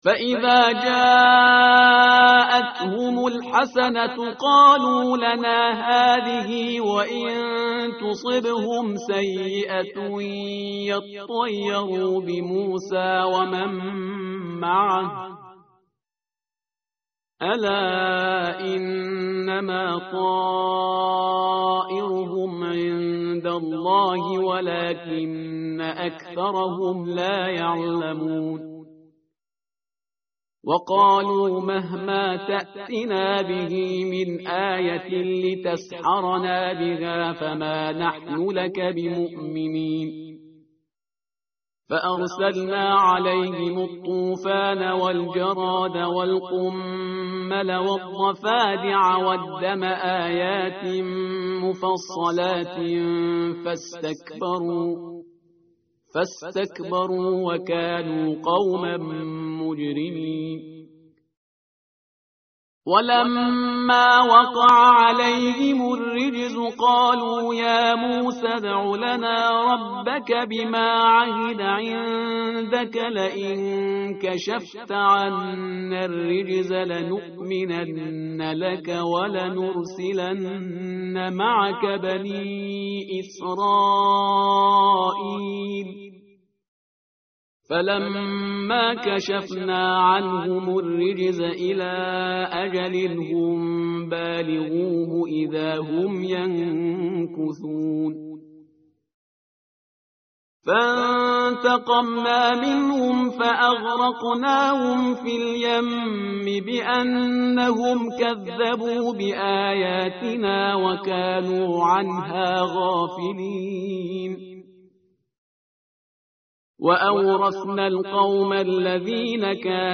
tartil_parhizgar_page_166.mp3